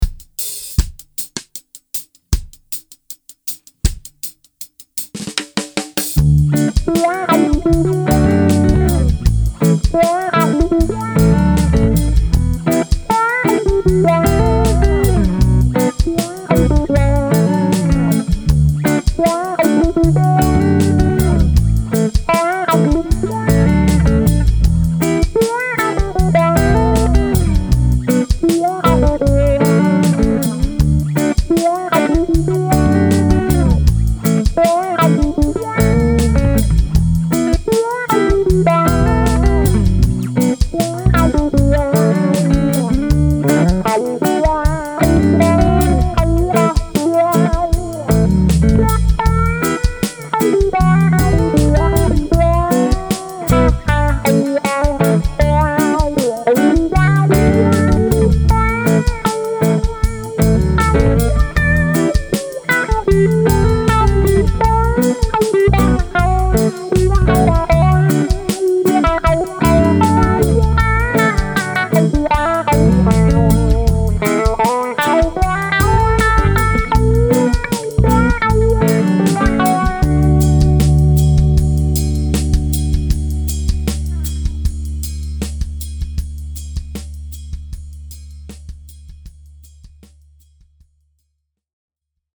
So I recorded a quick reggae clip to demonstrate the guitar’s clean tones.
The rhythm track was recorded with the neck pickup, while the lead track was recorded with the bridge pickup. Both guitars were plugged into an Aracom VRX18 running into a custom Aracom 1 X 12 with a Jensen Jet Falcon speaker. For the lead, I used a VOX Big Bad Wah. Also, while the clip is mastered a bit with some compression and reverb, the guitars were not EQ’d. Very nice tone.
What impressed me about the guitar is that amazingly enough it has some very nice sustain.
reggae1.mp3